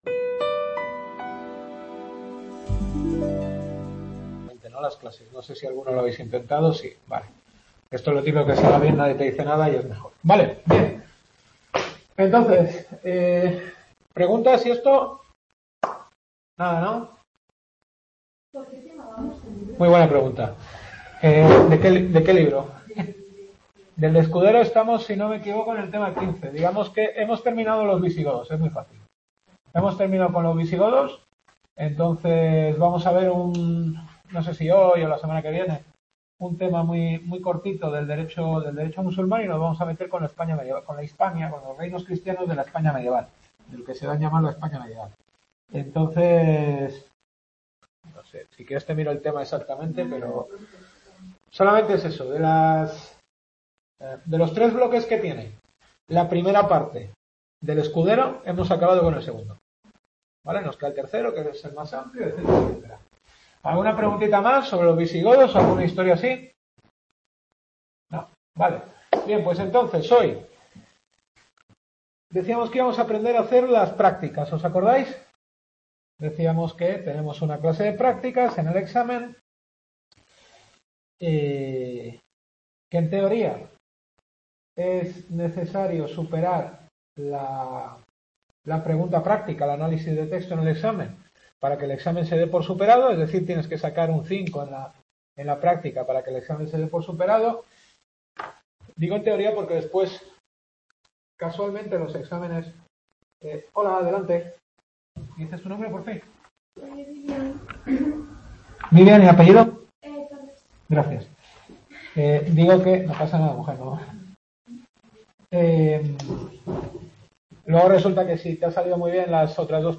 Historia del Derecho. Sexta clase.